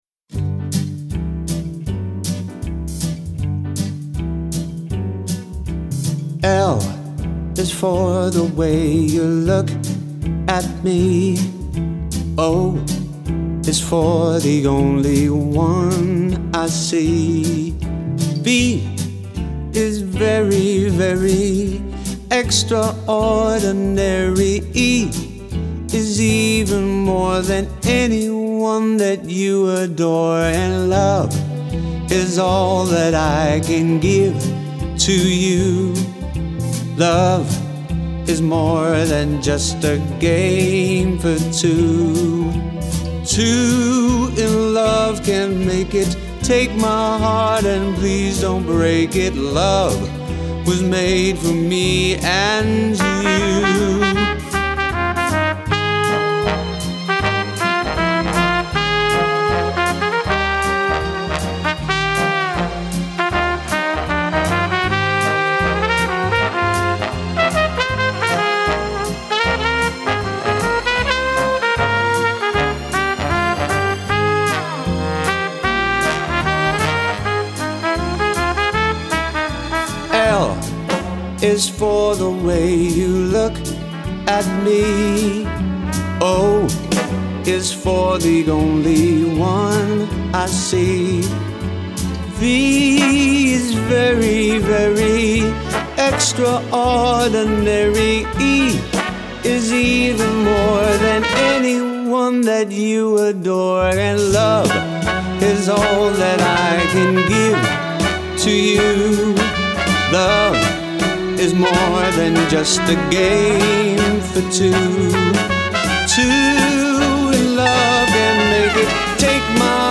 His strong vocals, personality and performance will make a great addition to any occasion.